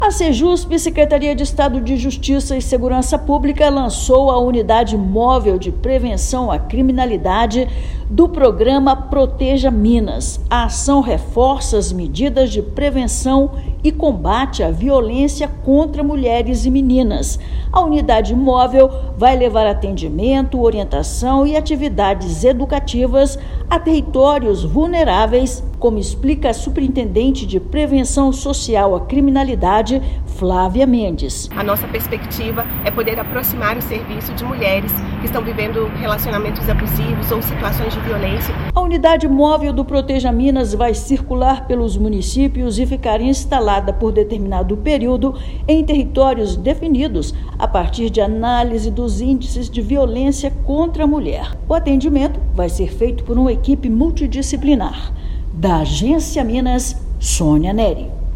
Unidade itinerante leva acolhimento, orientação e ações de prevenção a territórios vulneráveis. Ouça matéria de rádio.